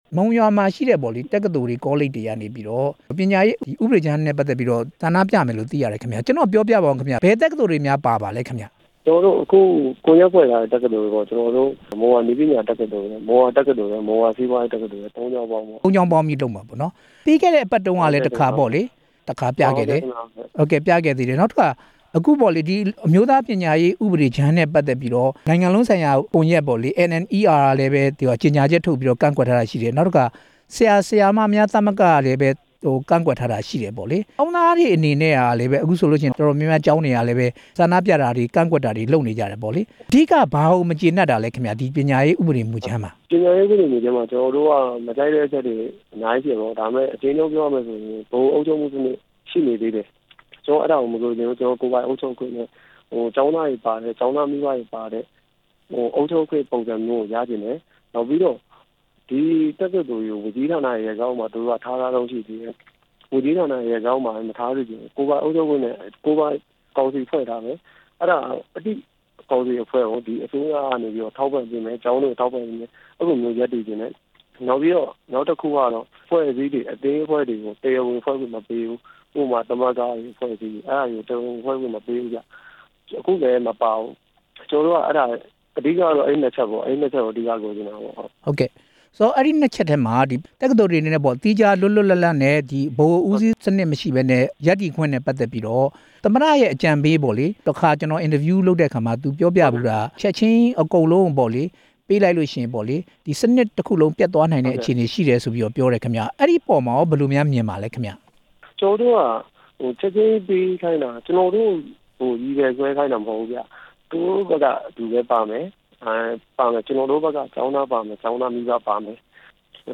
ပညာရေးဥပဒေမူကြမ်း မုံရွာတက္ကသိုလ်ကျောင်းသားတွေ ကန့်ကွက်ဆန္ဒပြတာ မေးမြန်းချက်